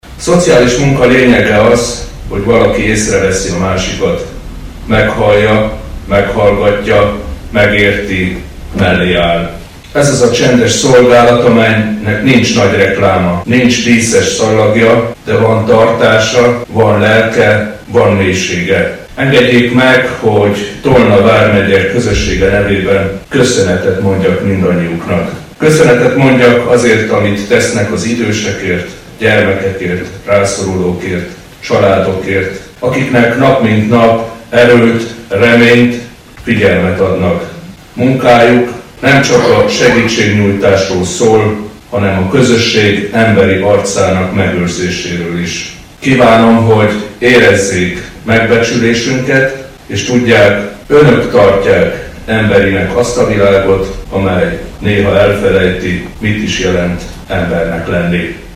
Dunaföldváron csütörtökön délelőtt ennek tiszteletére ünnepséget rendeztek a Városi Művelődési Központban, melyet megtisztelt jelenlétével Süli János országgyűlési képviselő és Orbán Attila is. A megyei közgyűlés elnökének ünnepi beszédéből hallanak részletet.